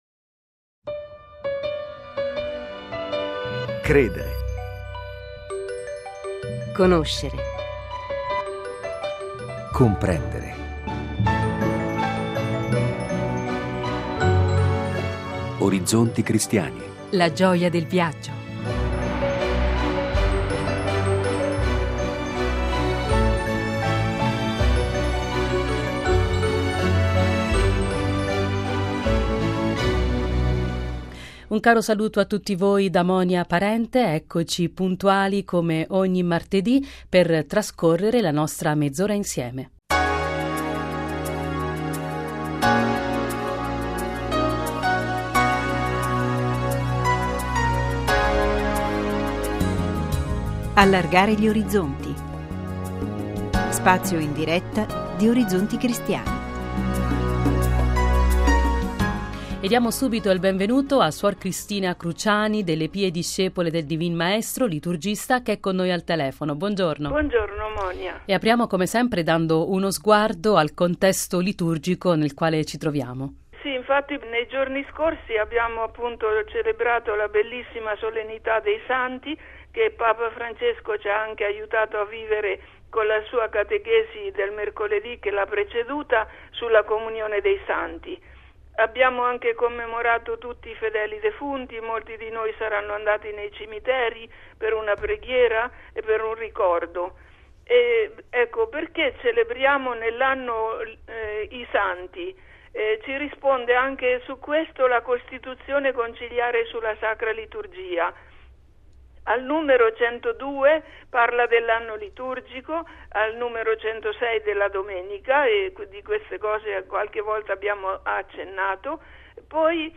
intervista Enzo Iacchetti, attore, conduttore televisivo e cantante